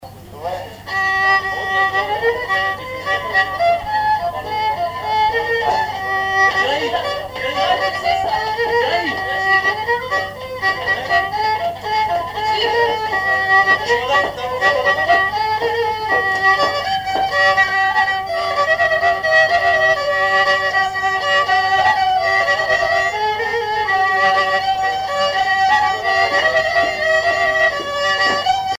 Répertoire d'un bal folk par de jeunes musiciens locaux
Pièce musicale inédite